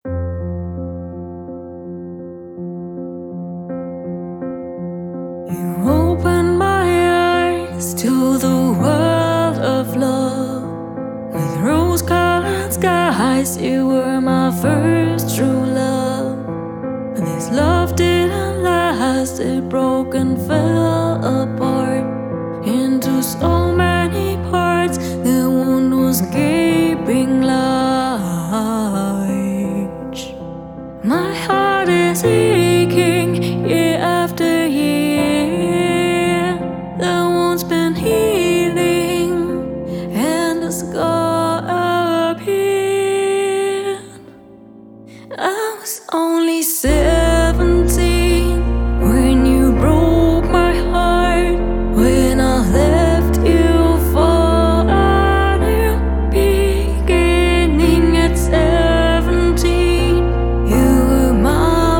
Pop Singer/songwriter
Stærkt inspireret af 80’ernes lyd